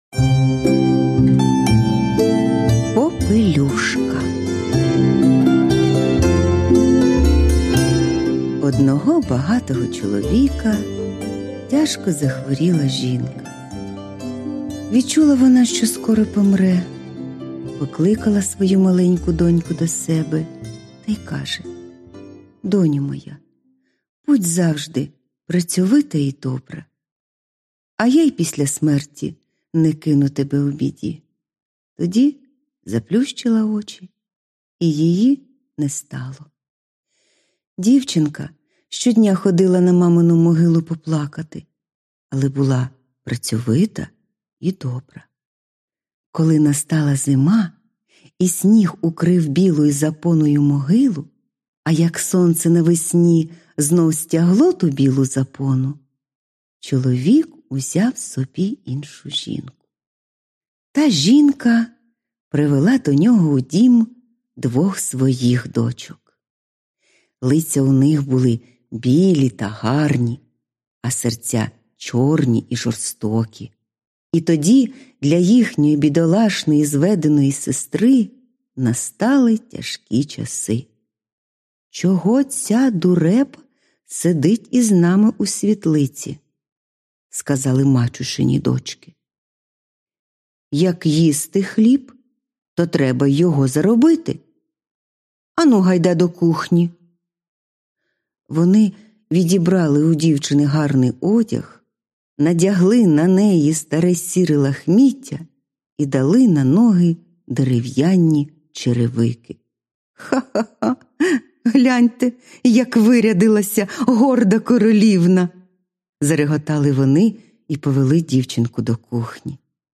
Аудіоказка Попелюшка